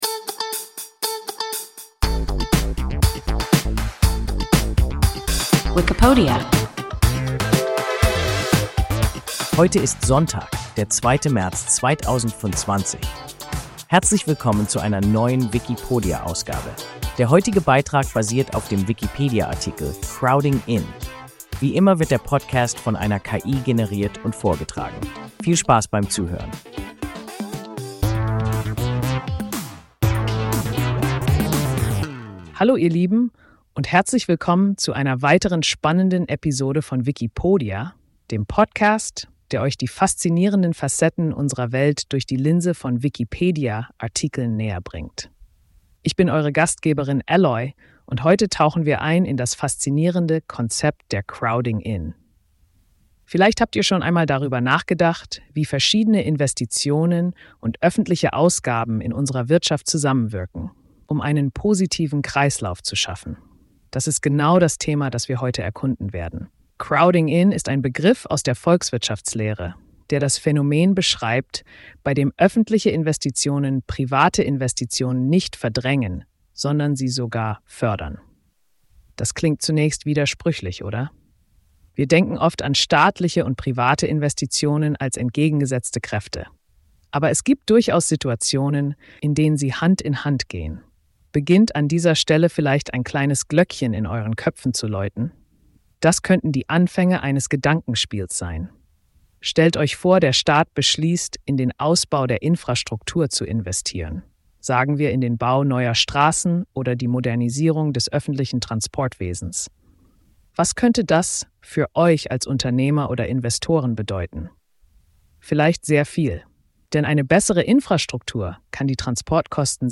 Crowding-in – WIKIPODIA – ein KI Podcast